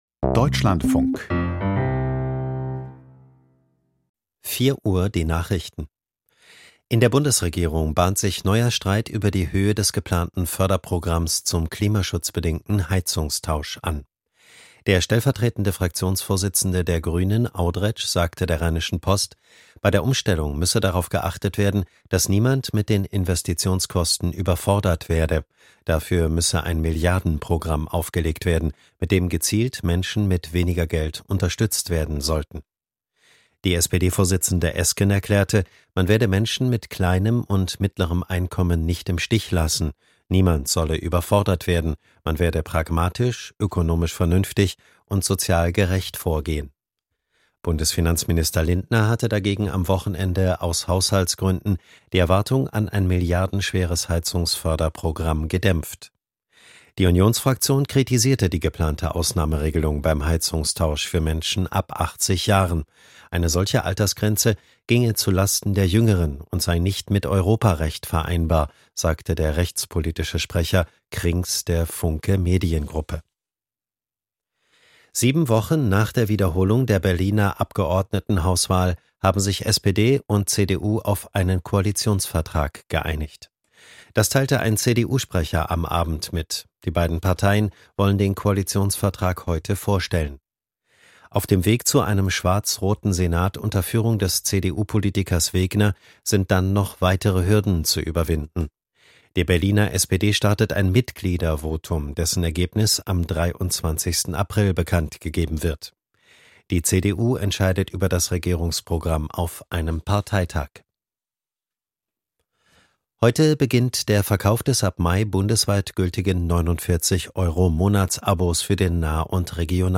Nachrichten vom 03.04.2023, 04:00 Uhr